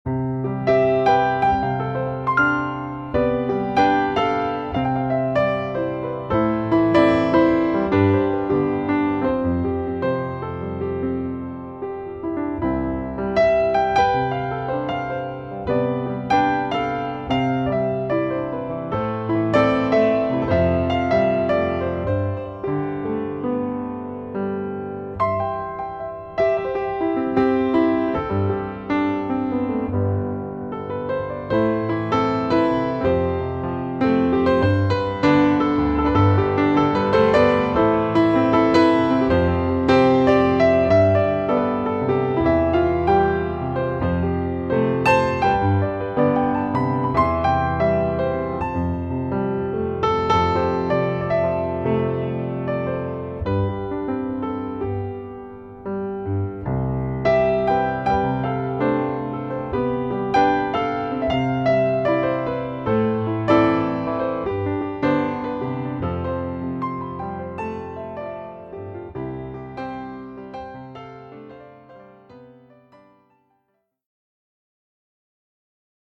ヒーリングＣＤ
優しいピアノの音がリラックス効果を高めます。